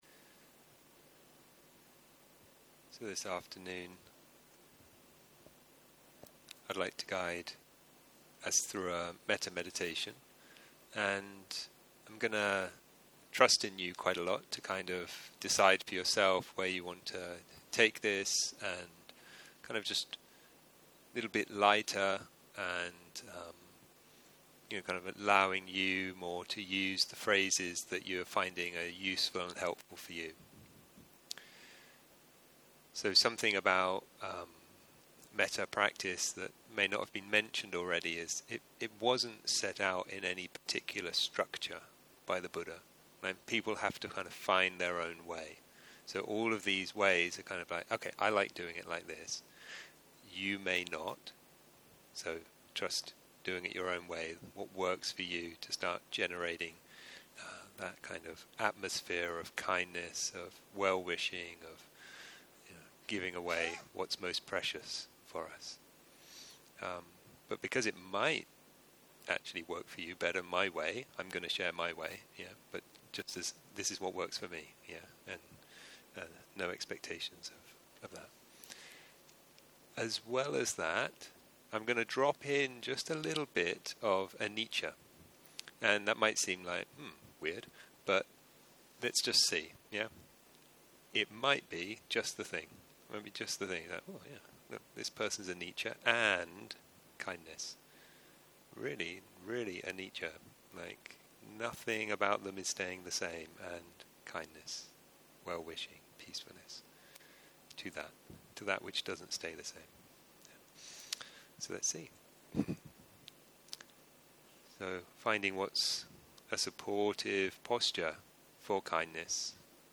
11.04.2023 - יום 5 - צהרים - מדיטציה מונחית - מטא ואניצ'ה - הקלטה 16 | תובנה